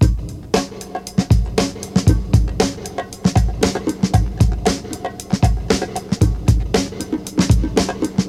116 Bpm Drum Beat G# Key.wav
Free breakbeat sample - kick tuned to the G# note.
116-bpm-drum-beat-g-sharp-key-ofY.ogg